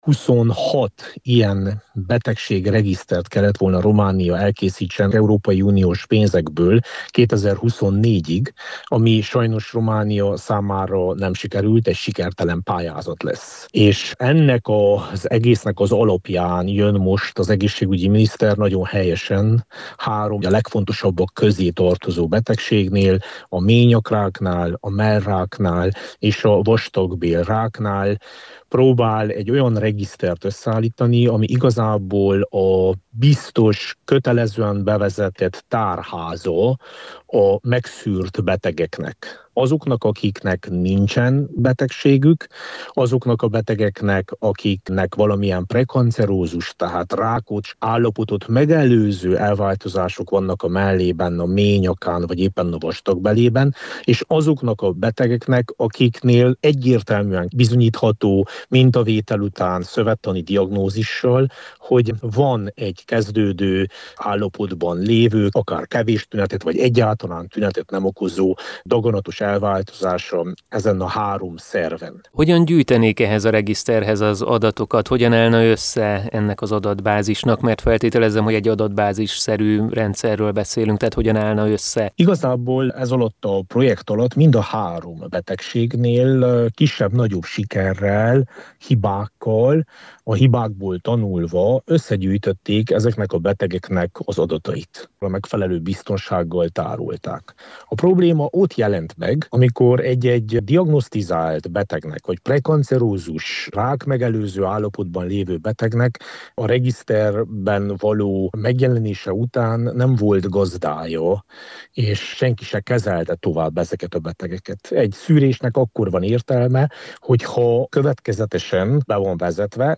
A regiszter nyújtotta lehetőségekről a képviselőház egészségügyi és családvédelmi bizottságának tagjával, Vass Leventével beszélgettünk.